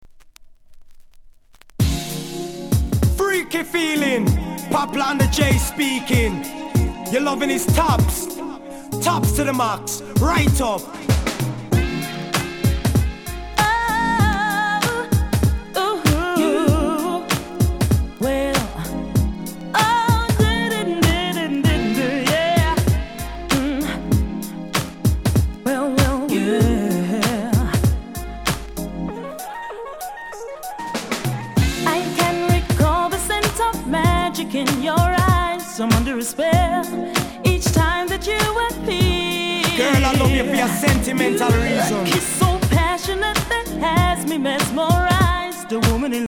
HIP HOP/R&B
人気R&B!!!